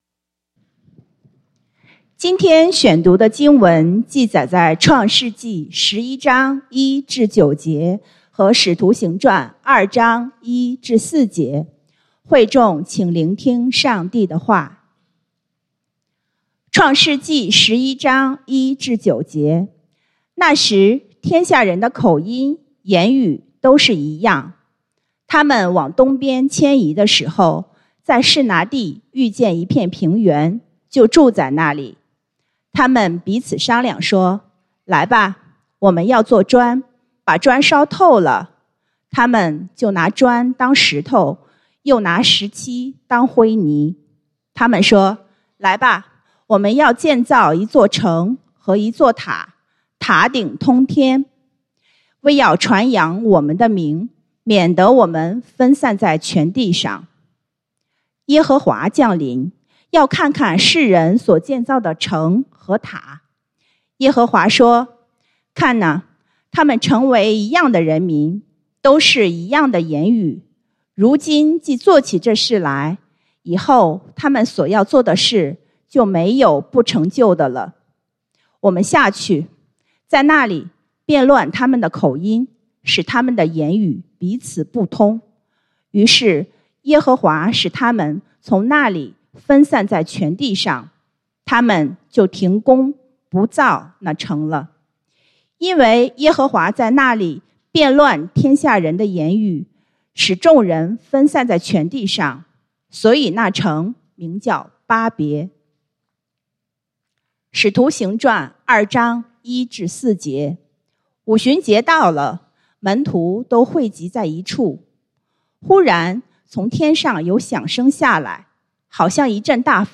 講道經文：創世記Genesis 11:1-9, 使徒行傳Acts 2:1-4